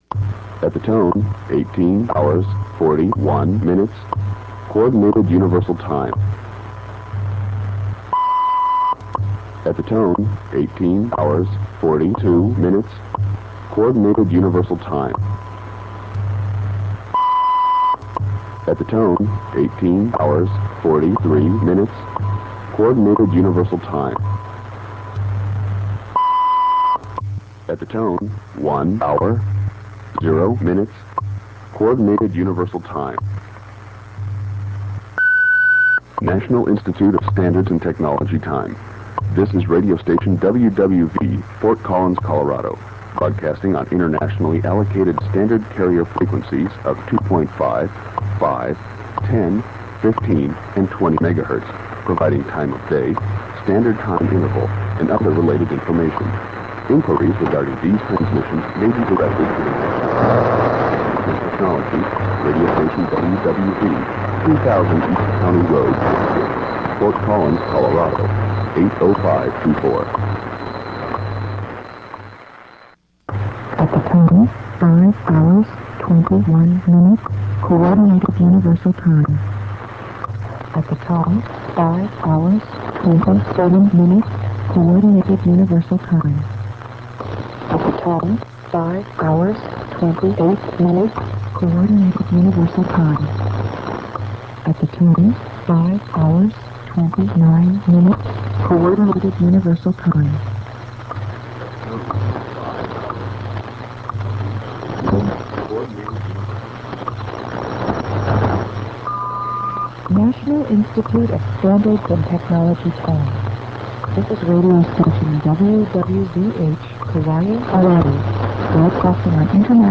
WWV & WWVH "mumbled" Digital Voices (1991)***